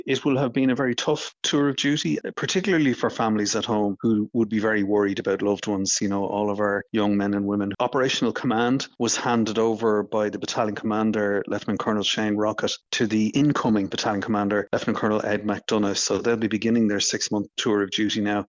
Independent Senator and Security Analyst, Tom Clonan, says the next tour has taken over: